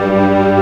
Index of /90_sSampleCDs/Giga Samples Collection/Organ/MightyWurltzBras